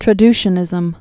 (trə-dshə-nĭzəm, -dy-)